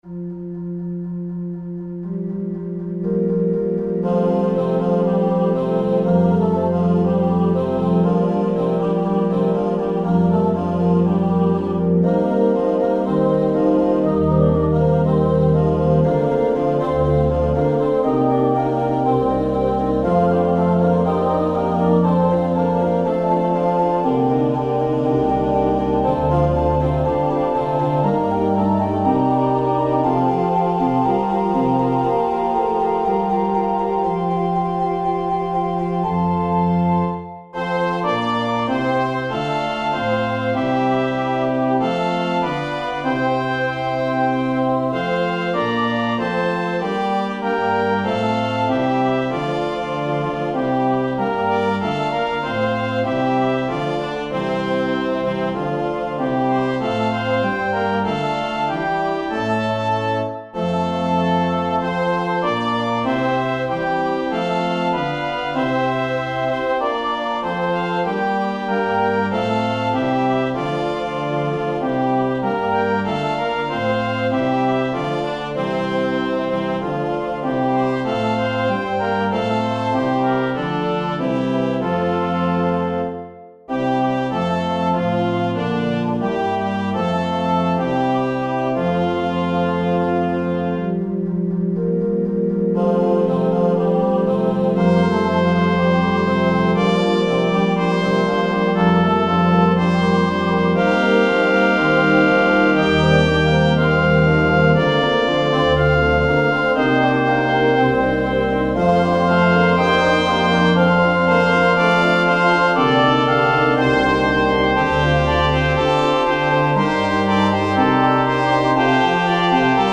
1998: Two-part Mixed, 2 Trumpets, Organ, Opt. Assembly